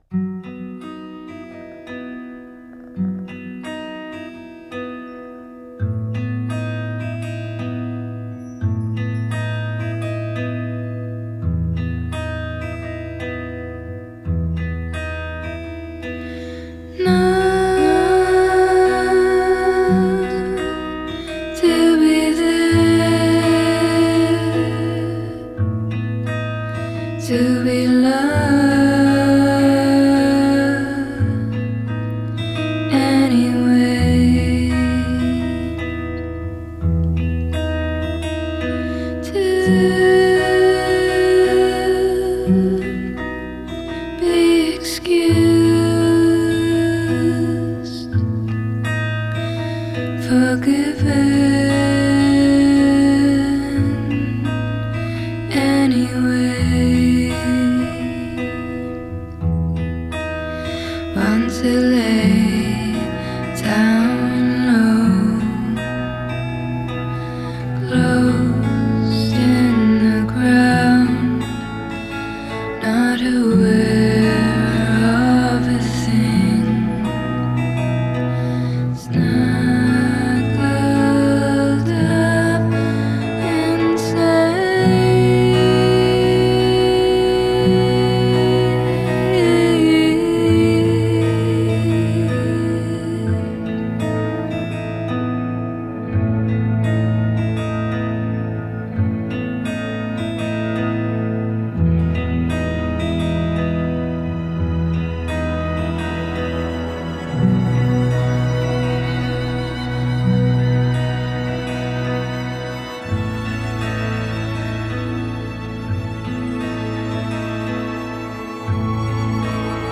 C'est un folk hypnotisant